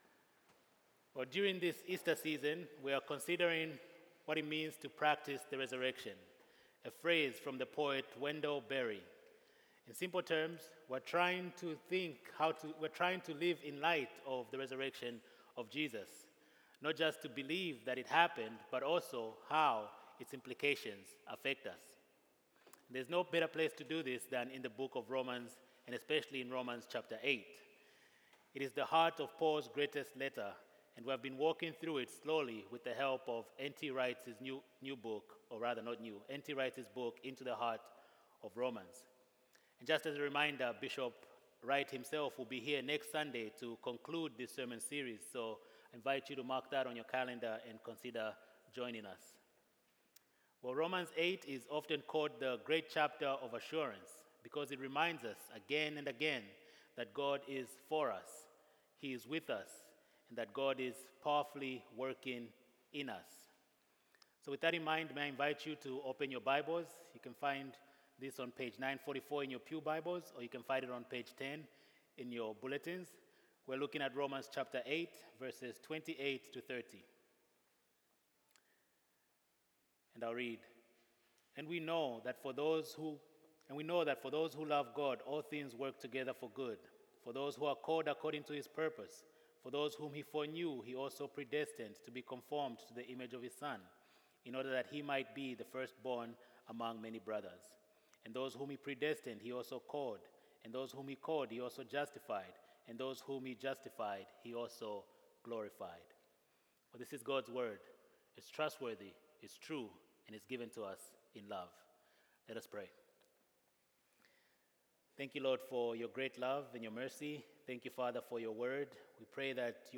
When it feels like we have nothing to hold on to, we can find confidence in a God who is working all things together for our good. Watch this sermon as we consider the beauty and freedom of assurance in Christ.